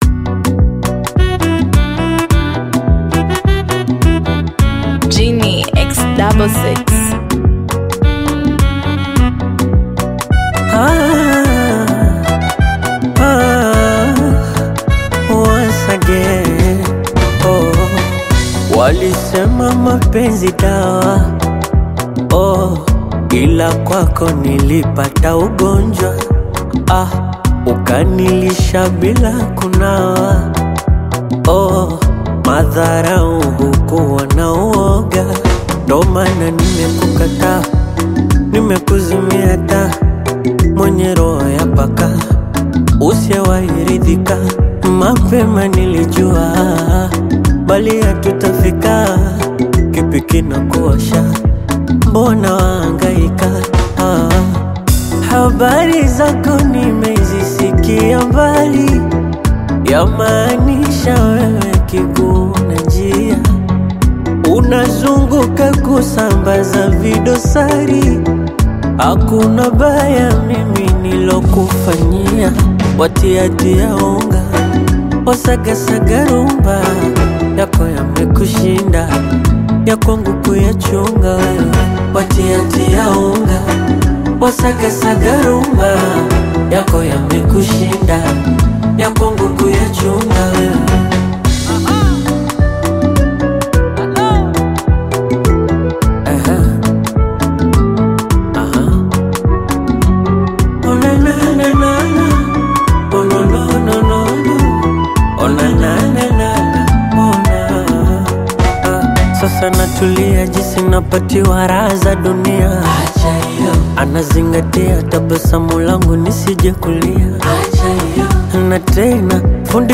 mid-tempo Bongo Flava track
Genre: Bongo Flava